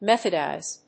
音節meth・od・ize 発音記号・読み方
/méθədὰɪz(米国英語)/